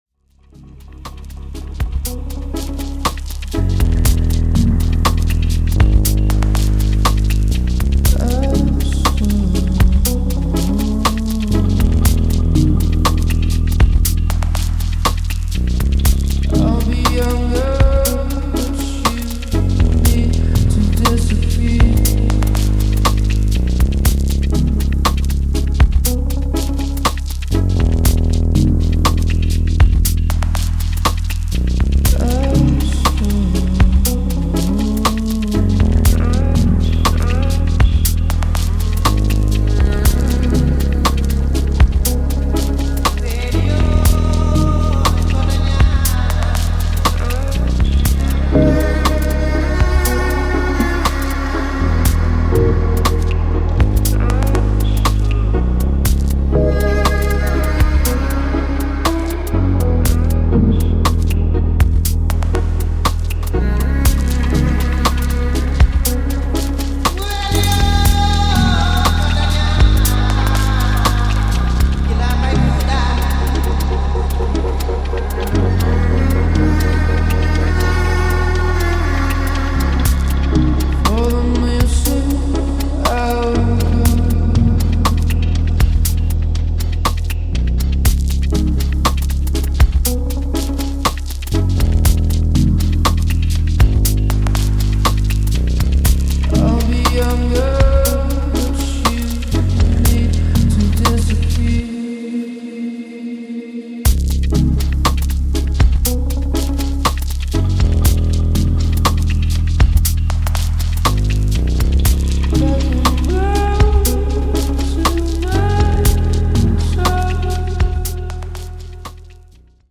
Electronix Techno Dub